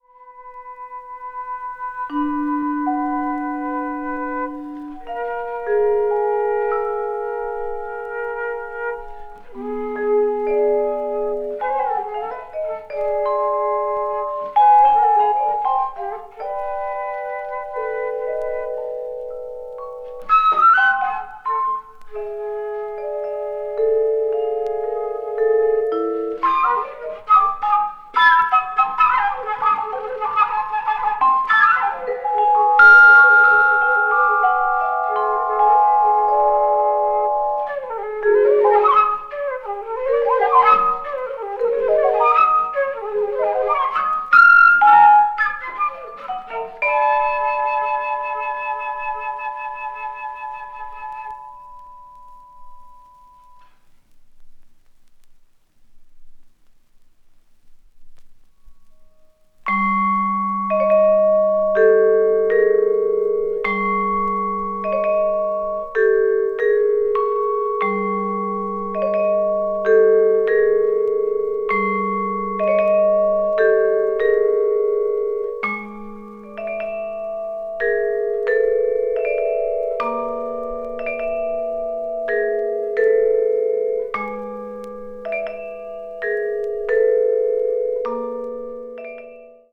同年にドイツ・ベルリンで行われたソロ・コンサートのライヴ・レコーディング音源を収録。フルート、ヴィブラフォン、バス。
とくにバス・クラリネットのソロが神経細胞にビンビン響いてきます。